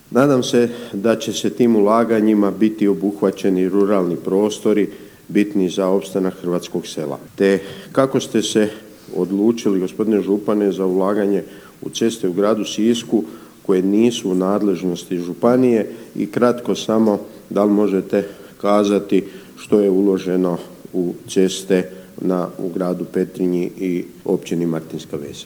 Na Županijskoj skupštini, održanoj u četvrtak, 22. prosinca 2022. godine, tijekom Aktualnog sata vijećnik Mijo Latin u svom pitanju osvrnuo se na ulaganja u ceste na području Petrinje, Martinske Vesi, ali i Siska, što nije u nadležnosti Županije.